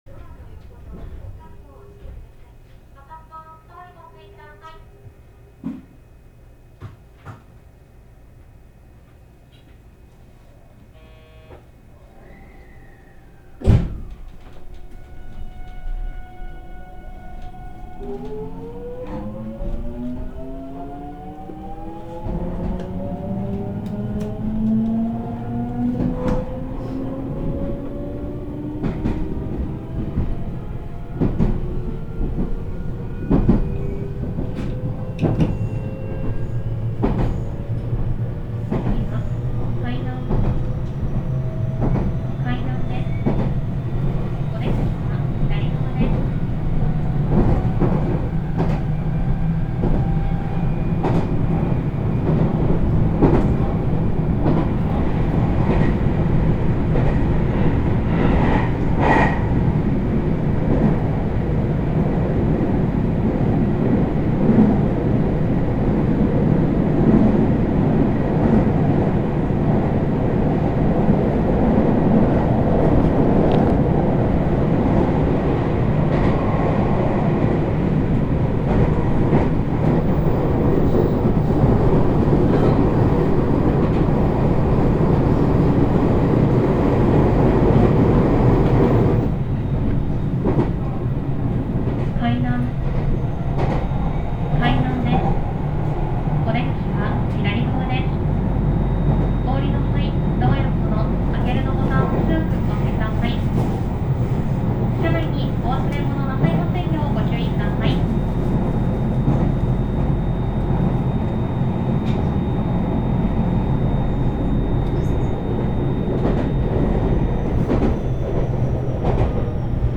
走行音
録音区間：黒江～海南(お持ち帰り)